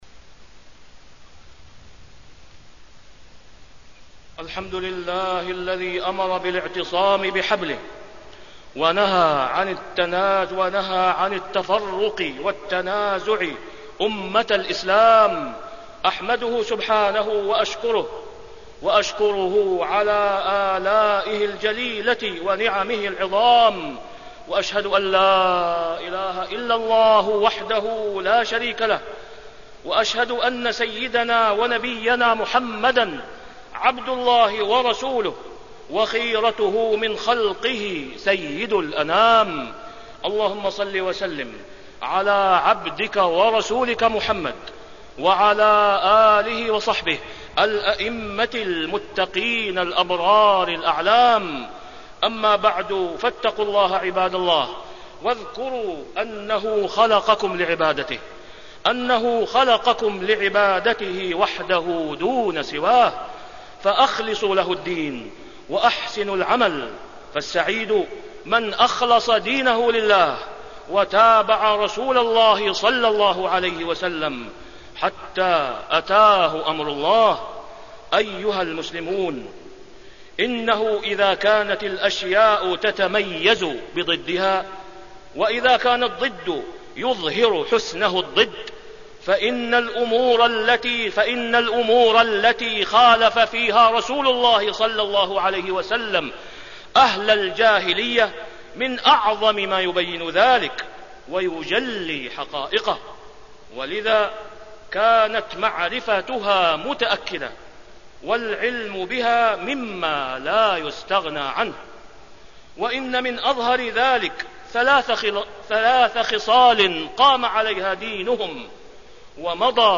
تاريخ النشر ١١ ربيع الأول ١٤٢٨ هـ المكان: المسجد الحرام الشيخ: فضيلة الشيخ د. أسامة بن عبدالله خياط فضيلة الشيخ د. أسامة بن عبدالله خياط إن الله يرضى لكم ثلاثا